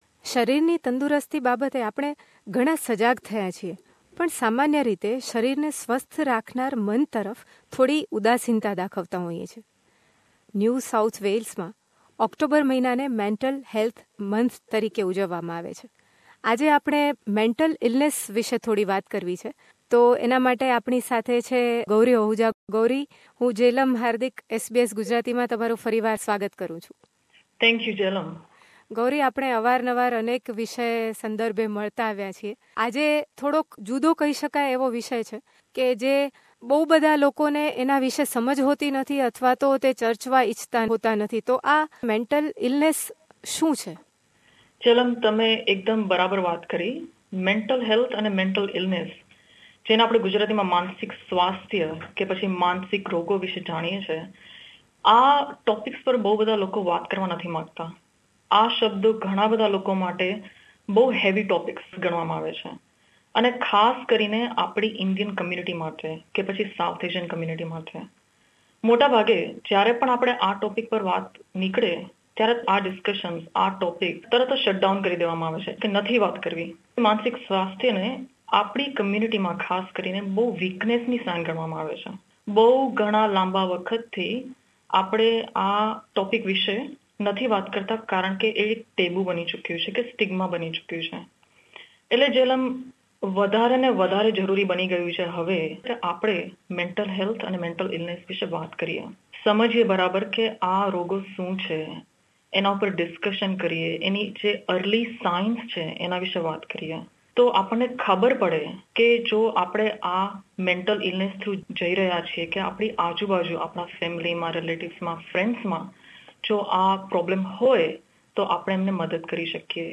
માનસિક સ્વાસ્થ્ય માટેની જાગૃતિ લાવવા 'હાથી ઈન ધ રૂમ' નામની સંસ્થાએ 22 ઓક્ટોબરે સિડનીમાં એક મૅન્ટલ હૅલ્થ વર્કશૉપનું આયોજન કર્યું છે. વિગતે જાણીએ આ વાતચીતમાં.